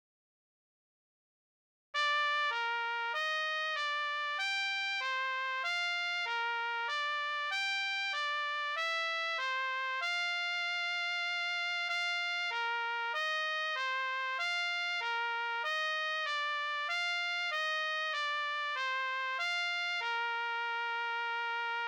D=Counter-melody/Harmony/Bass Part-for intermediate to experienced players